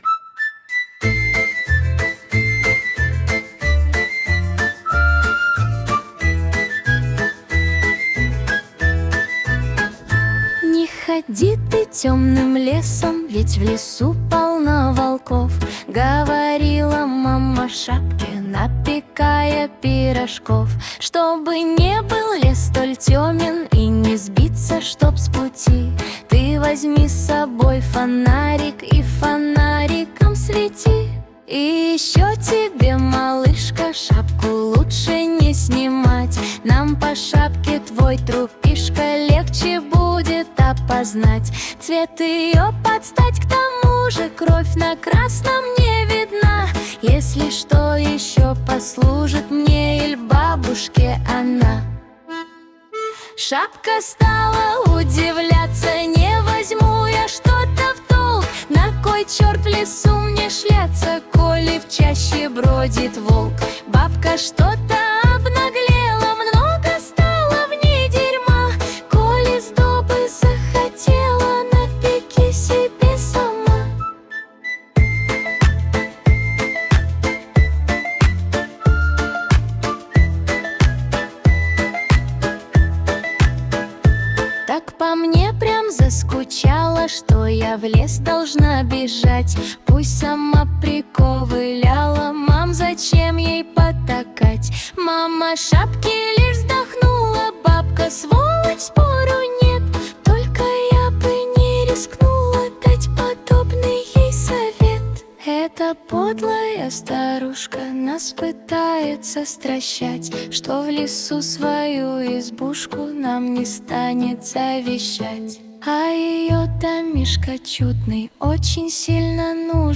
шуточная песня.